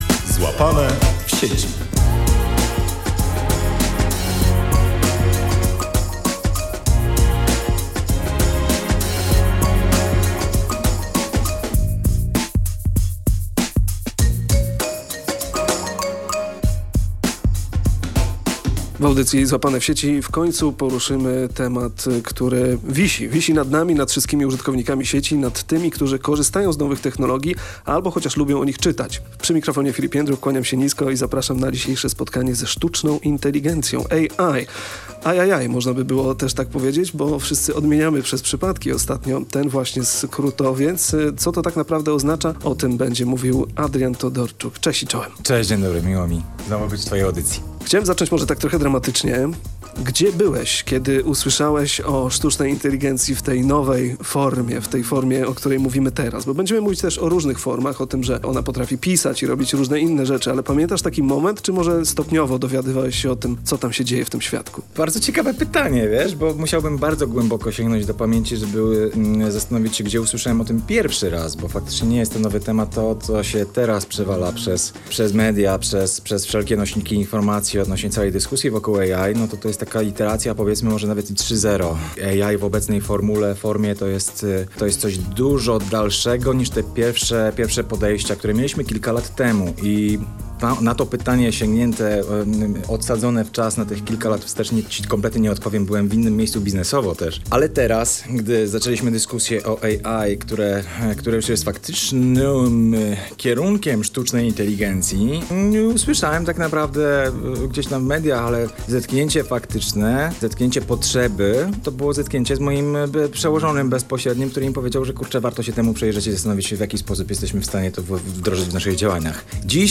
rozmawiamy o możliwościach, ciekawostkach i potencjalnych zagrożeniach.